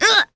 Glenwys-Vox_Damage_03.wav